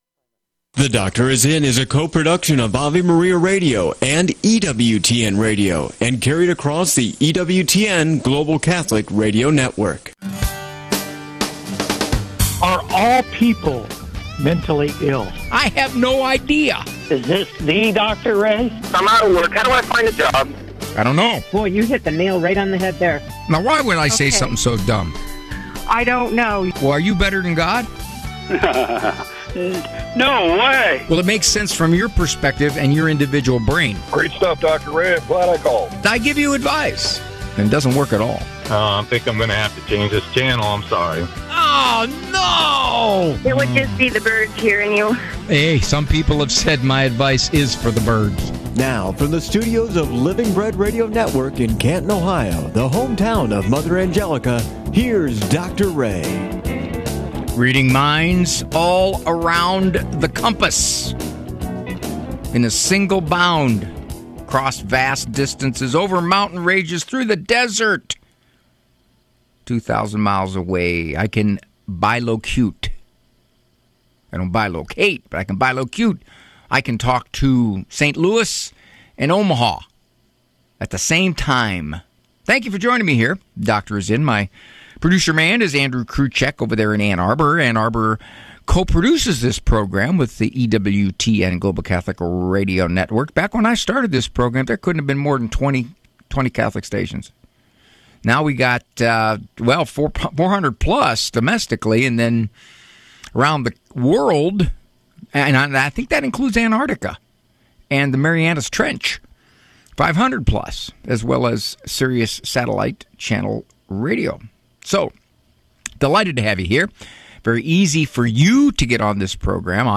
takes your calls!